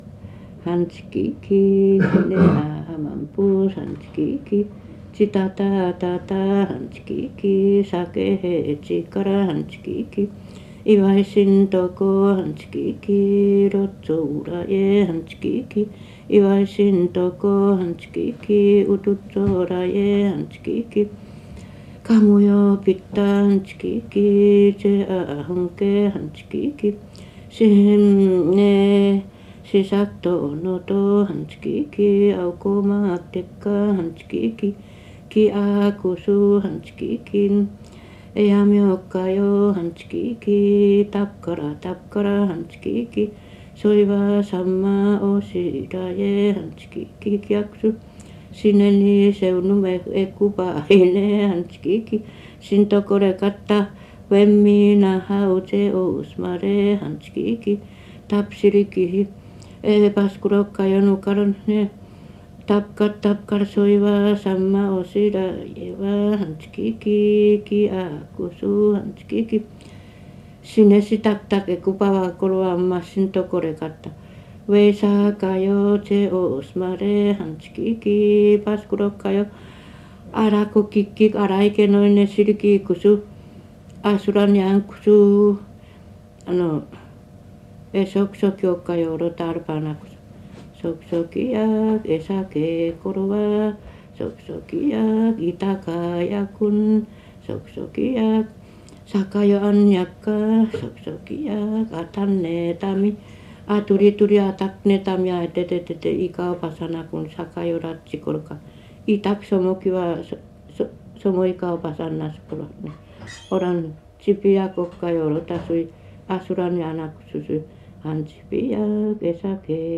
[15-12神謡 mythic epics]【アイヌ語】2:37